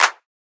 Clap (Genius).wav